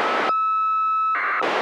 Неизвестные данные на 164,925